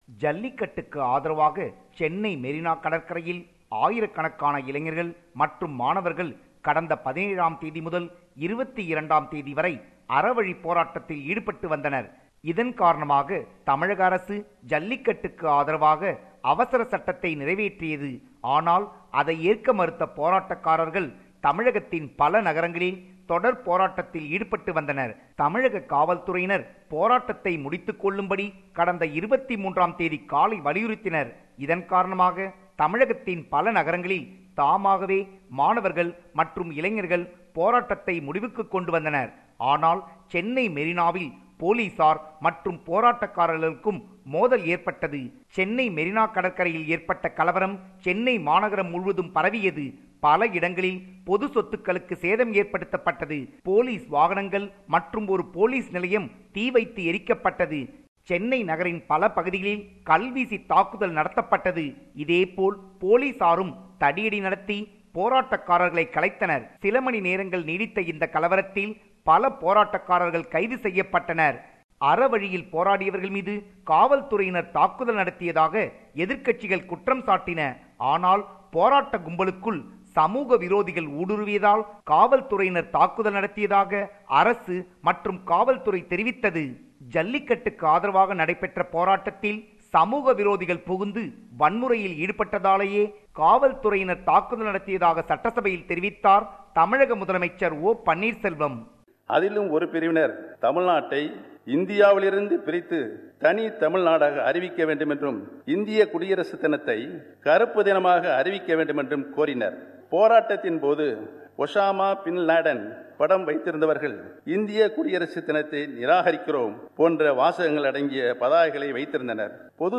compiled a report focusing on major events/news in Tamil Nadu